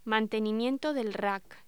Locución: Mantenimiento del RAC
voz